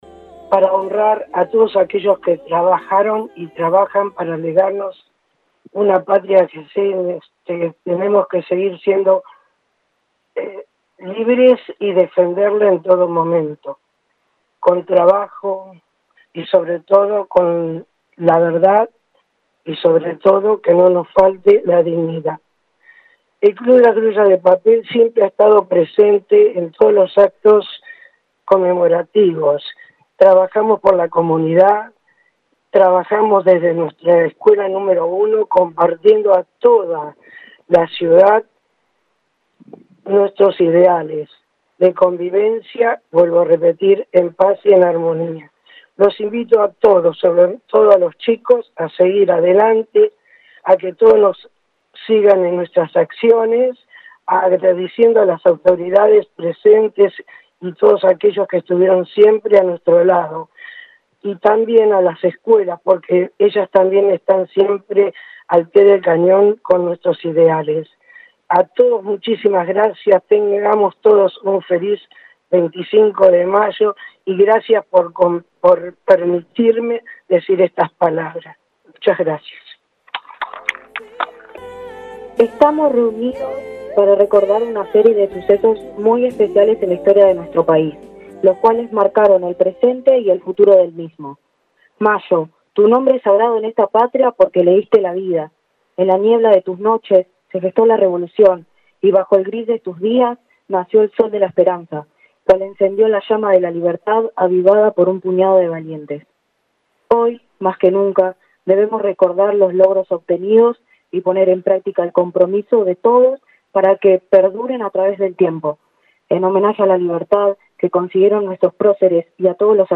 (galería de imágenes) Con la presencia de autoridades municipales, invitados especiales, representantes de distintas instituciones locales, abanderados y escoltas de establecimientos educativos y público en general, se llevó a cabo en la mañana de hoy sábado en la plazoleta del Barrio «25 de Mayo» el acto centralizado por el 214° Aniversario de la Revolución de Mayo. Luego del izamiento de la bandera argentina en el mástil central se entonaron las estrofas del Himno Nacional Argentina.
Finalmente, al dejar su mensaje, el intendente Alberto Gelené resaltó las palabras de la docente.